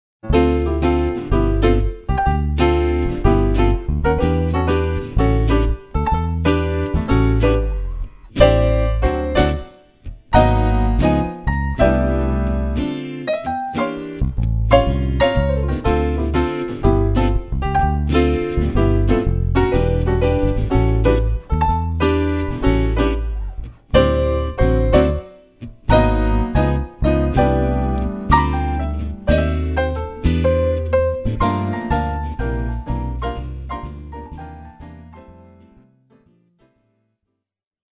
Jazz Standards/Big Band